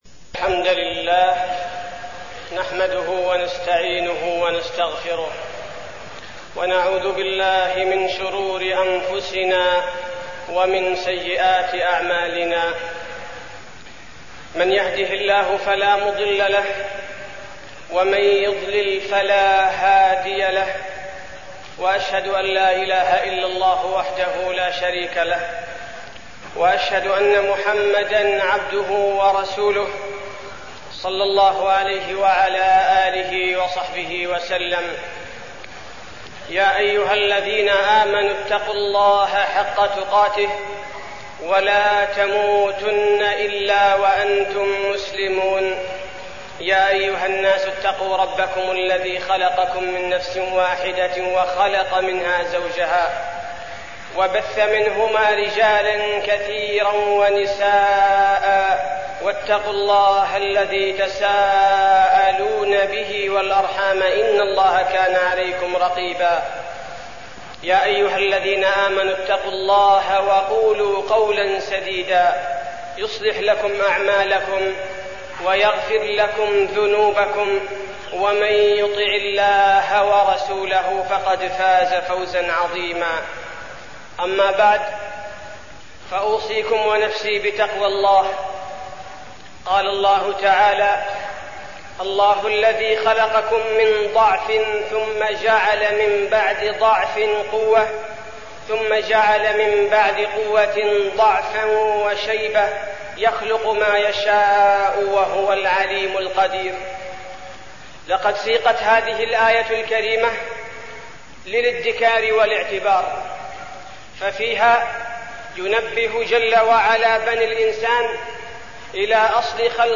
تاريخ النشر ٢٣ جمادى الآخرة ١٤١٨ هـ المكان: المسجد النبوي الشيخ: فضيلة الشيخ عبدالباري الثبيتي فضيلة الشيخ عبدالباري الثبيتي الشباب